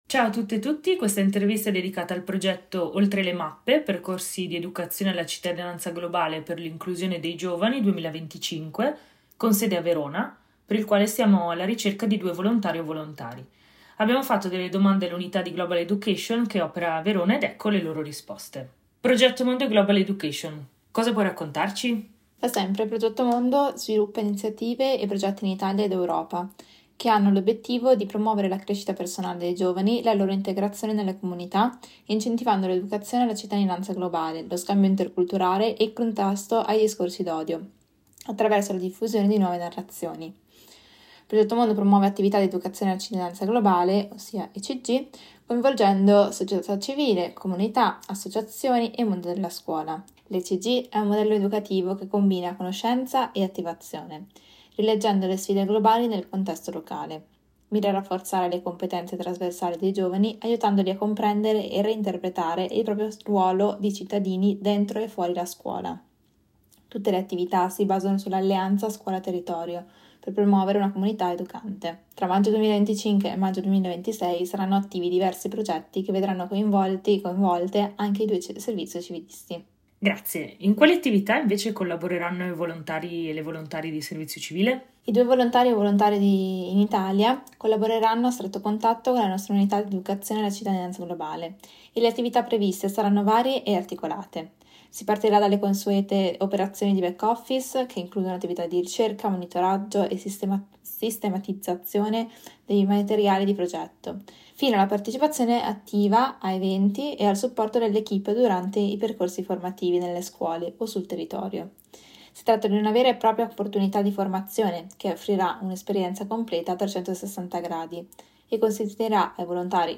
INTERVISTA 1